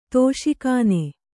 ♪ tōśi kāne